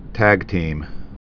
(tăgtēm)